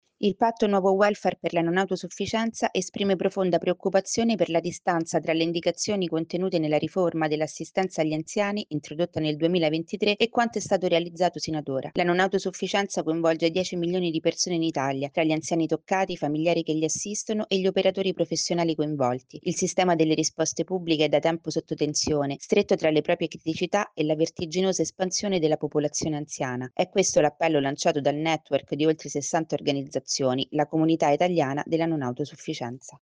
Secondo il Patto per un Nuovo Welfare sulla Non Autosufficienza occorre rilanciare la riforma sull’assistenza agli anziani. Il servizio